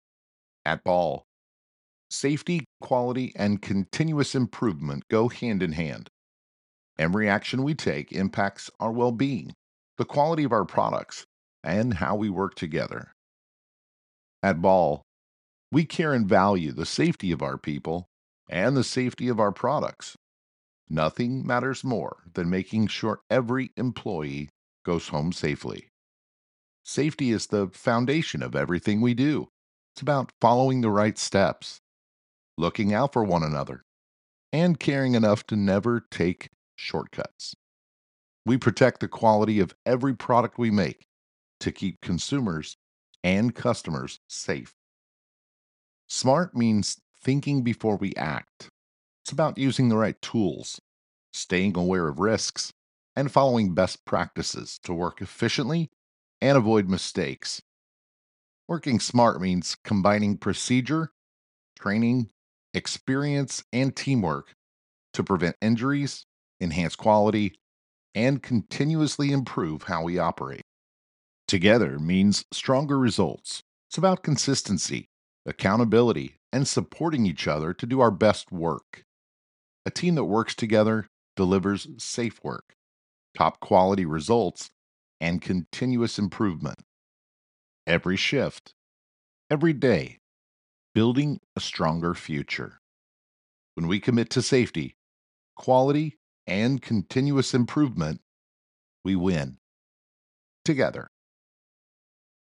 Narration - Corporate Safety Video (1:45)